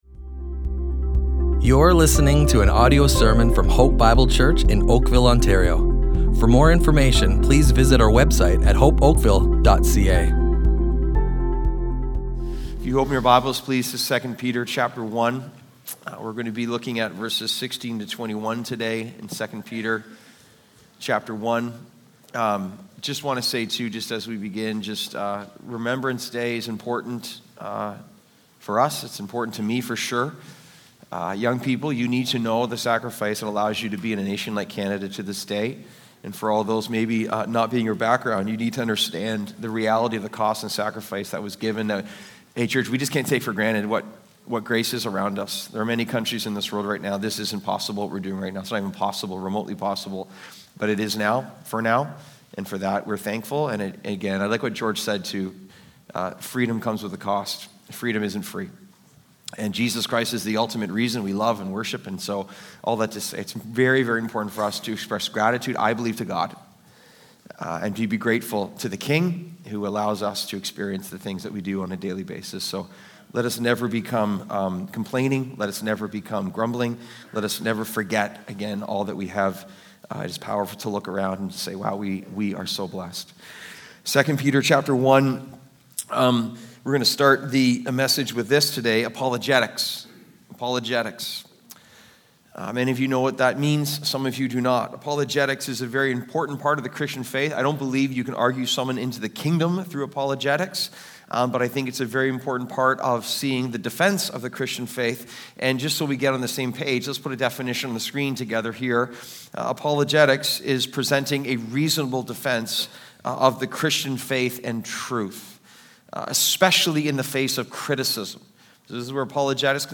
Hope Bible Church Oakville Audio Sermons Character, Corruption, and the Second Coming // Every Reason to Believe!